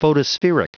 Prononciation du mot photospheric en anglais (fichier audio)
Prononciation du mot : photospheric